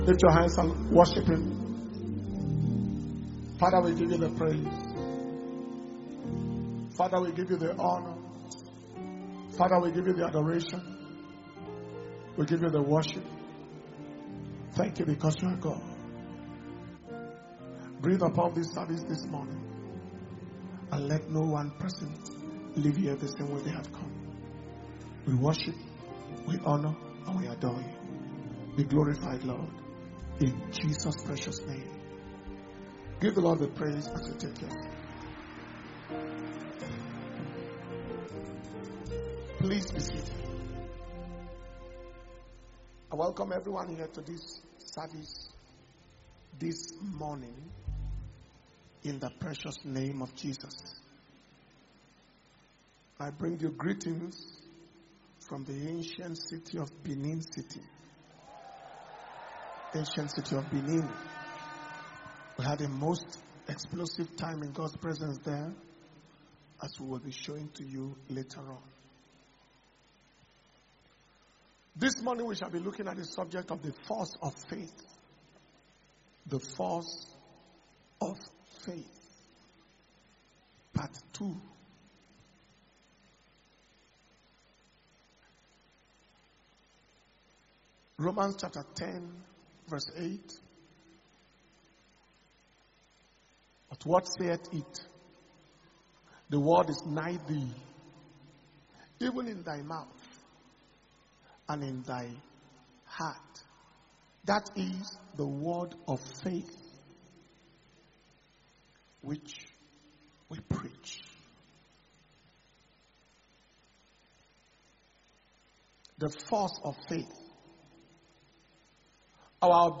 April 2022 Testimonies And Thanksgiving Service – Sunday 24th April 2022